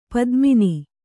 ♪ padmini